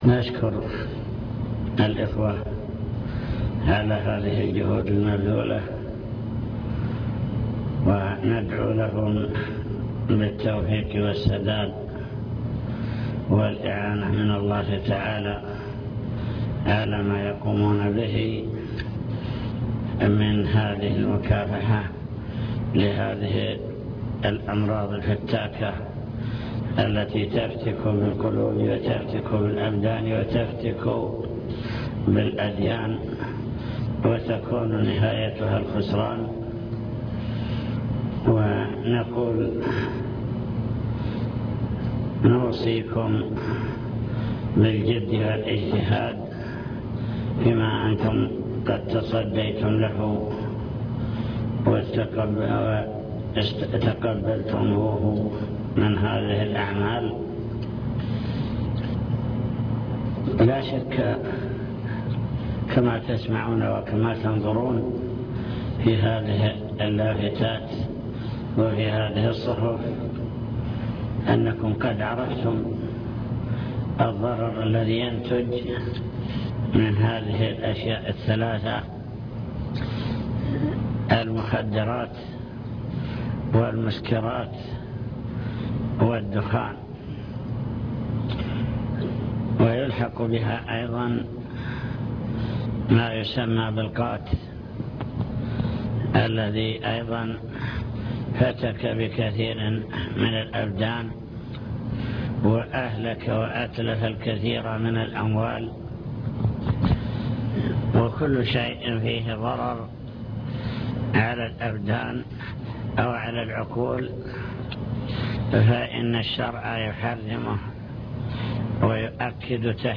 المكتبة الصوتية  تسجيلات - لقاءات  كلمة للعاملين في مكافحة المخدرات أضرار المخدرات